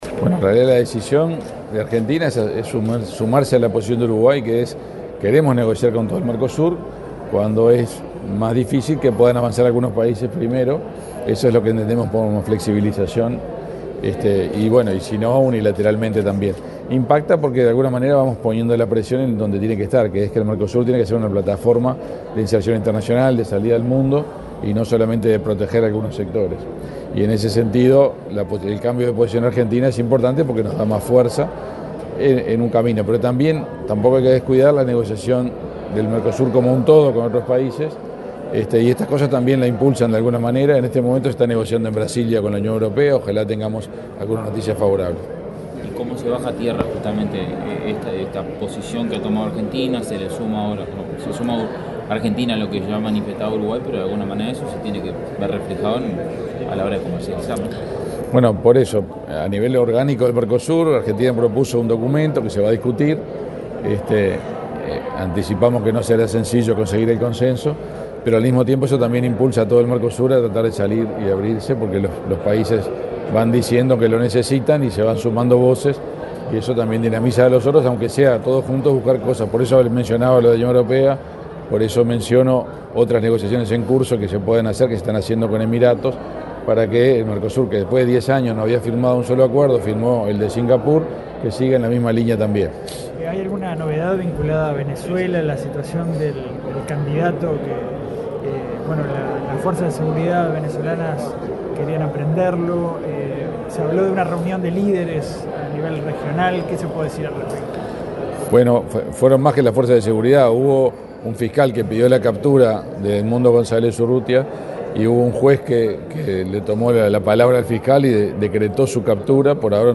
Declaraciones del canciller Omar Paganini
El canciller Omar Paganini participó, este jueves 4 en la Torre Ejecutiva, en el acto en el que se dieron detalles de la participación de Uruguay en